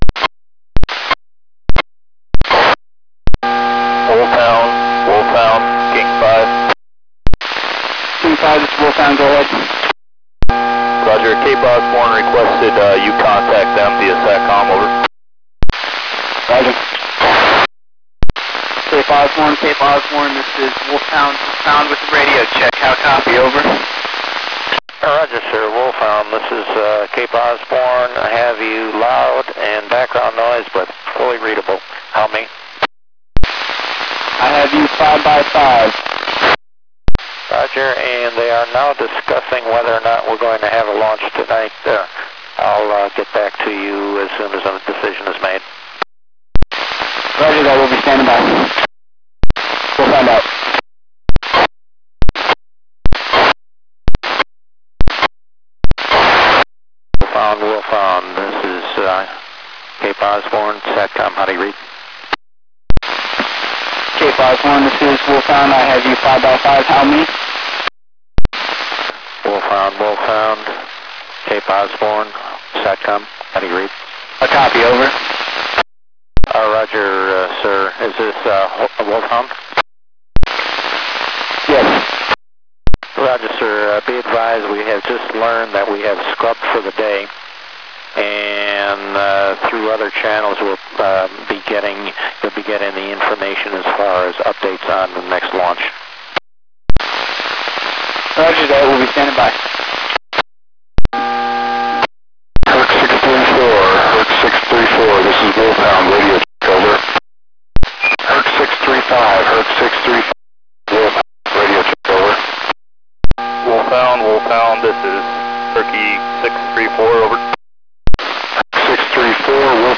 Shuttle #2 Discovery UHF downlink audio and the 261.875 MHz downlink launch support net comms. Recorded July 4 during STS-121 launch